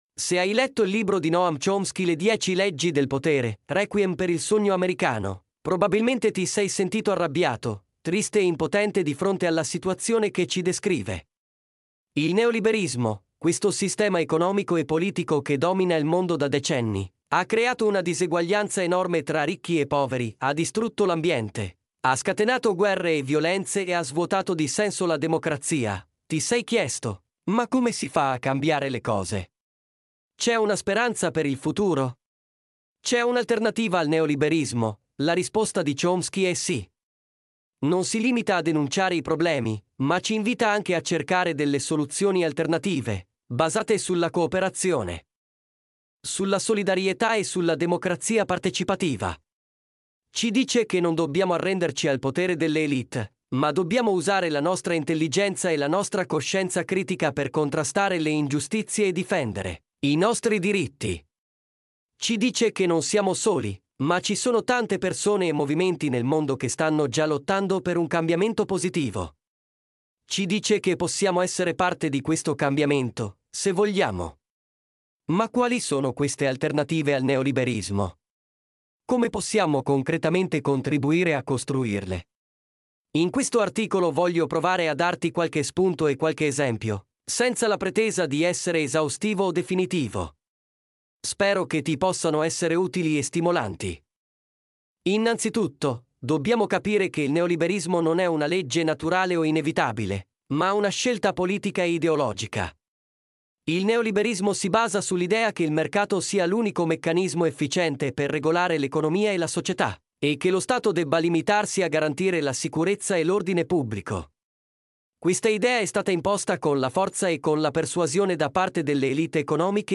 Se vuoi ascoltare in podcast quest’articolo puoi farlo qui sotto premendo il tasto play: tempo di ascolto 11.37 minuti
mp3-output-ttsfreedotcom-11_raHGb6GX.mp3